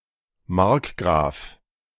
Etymologically, the word "margrave" (Latin: marchio, c. 1551) is the English and French form of the German noble title Markgraf (German pronunciation: [ˈmaʁkˌɡʁaːf]
De-Markgraf.ogg.mp3